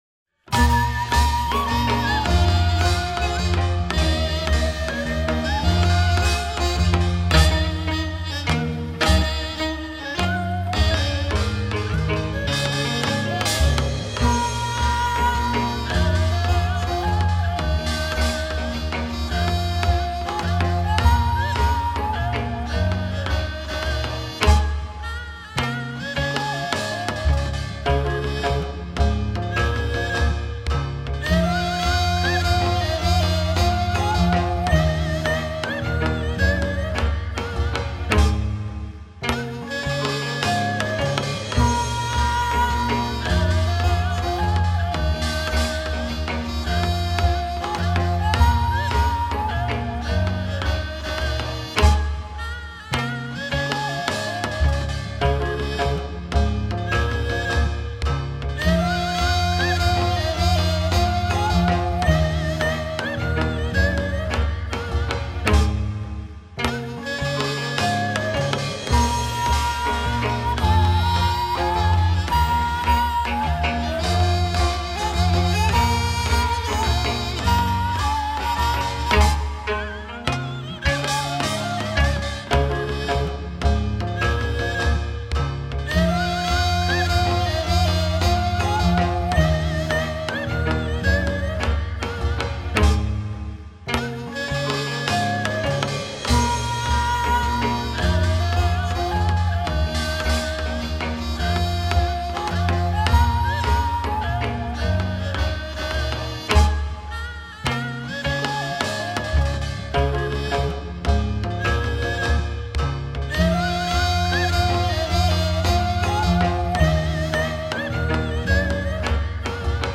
반주음악(MR)